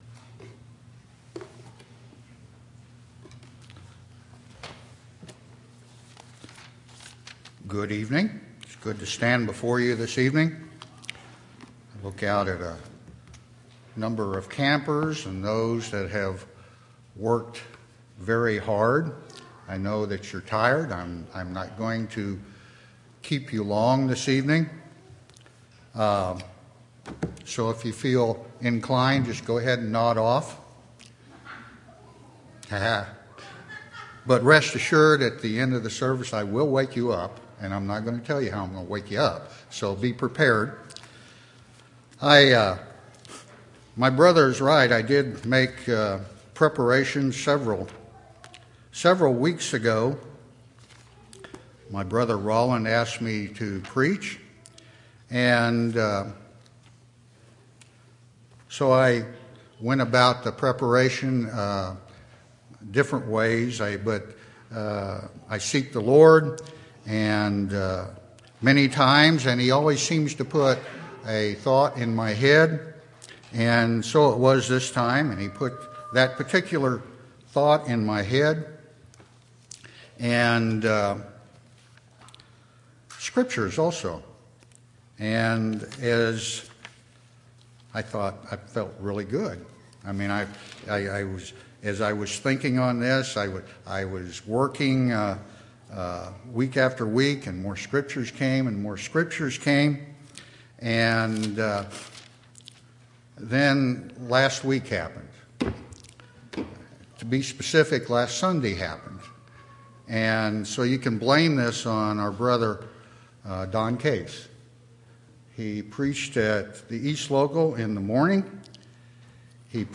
7/29/2016 Location: Missouri Reunion Event